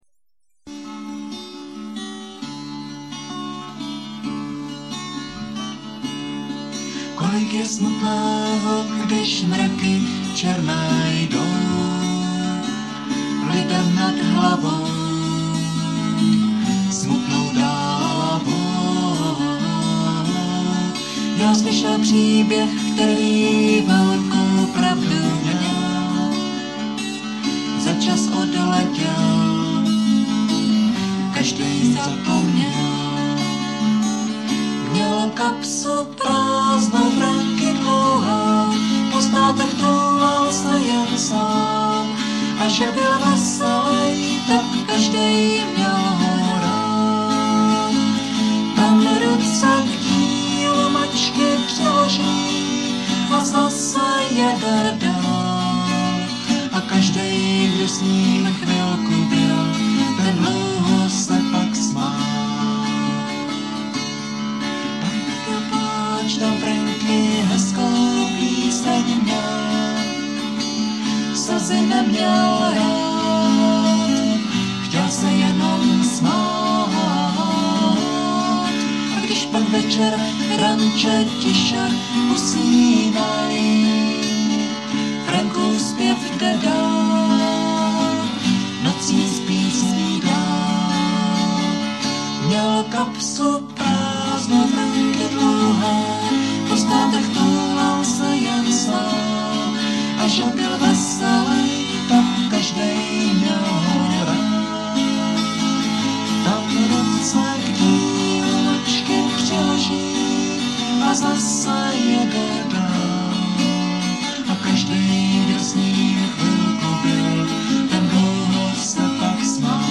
zpívejte na záznamy mp3 v podání 1 kytary a dvou hlasů